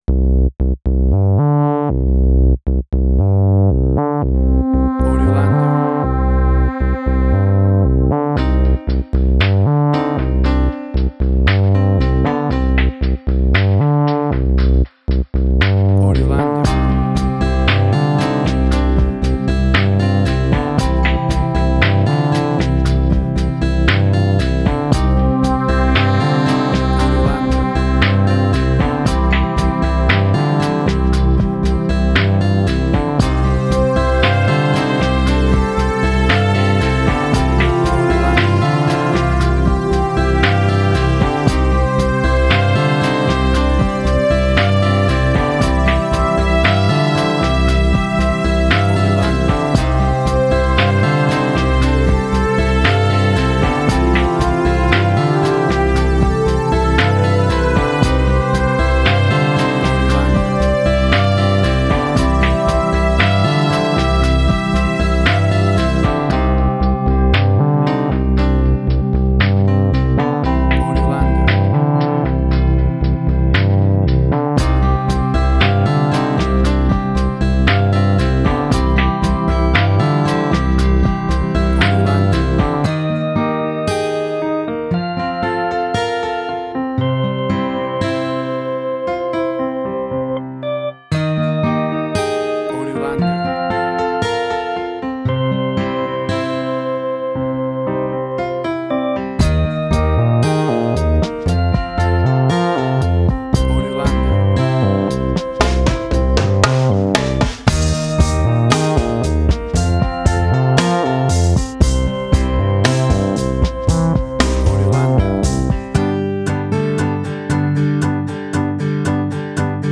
emotional music
Tempo (BPM): 58